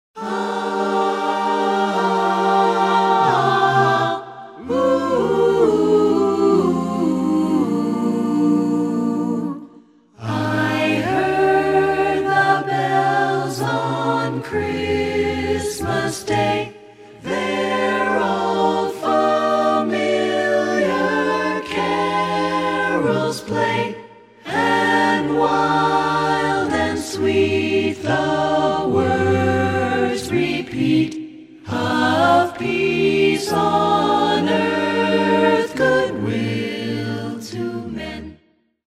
A CAPPELLA CHRISTMAS CAROLS